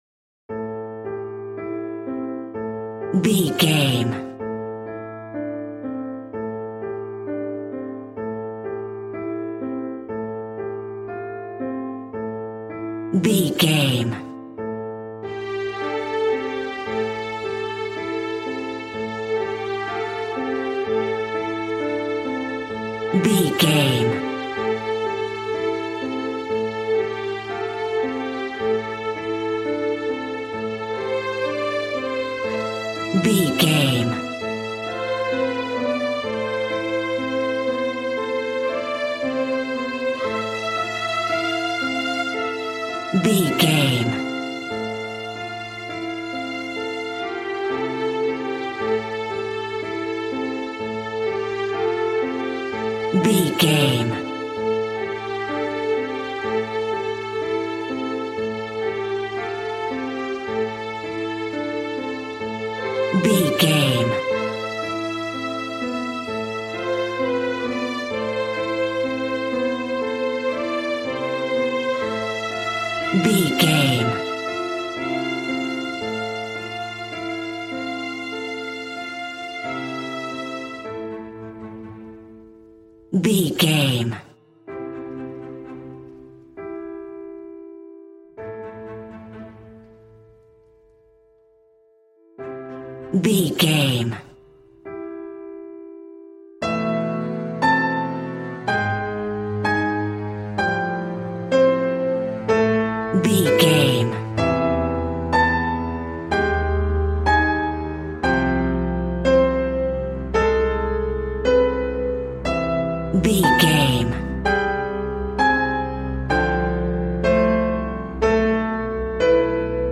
Regal and romantic, a classy piece of classical music.
Aeolian/Minor
regal
strings
brass